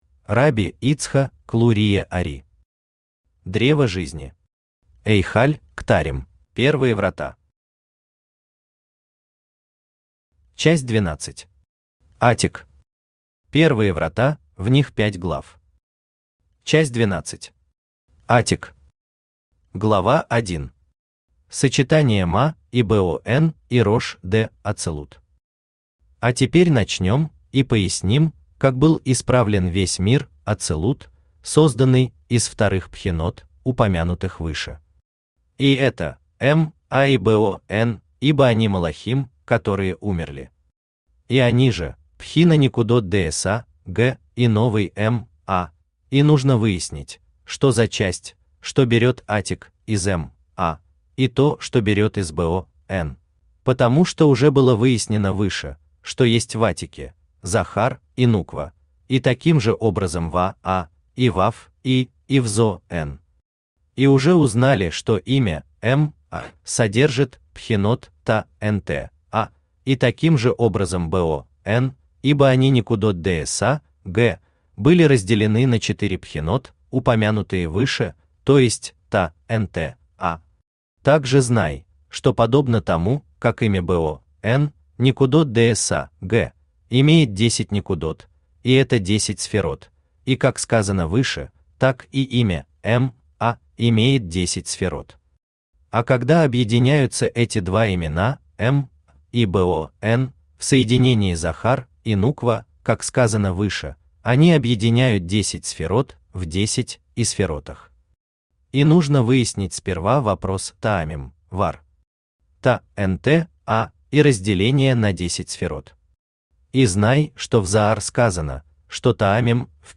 Аудиокнига Древо Жизни. Эйхаль Ктарим | Библиотека аудиокниг